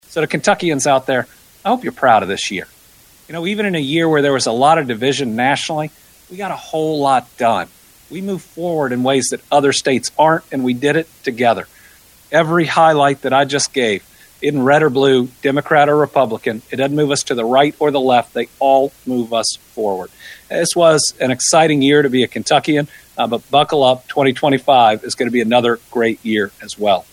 During last Thursday’s “Team Kentucky” update, Governor Andy Beshear noted the last 12 months can officially be recorded as the fourth-best economy in the state’s history — joining the first- and second-best also observed during his, and this Kentucky General Assembly’s, tenure.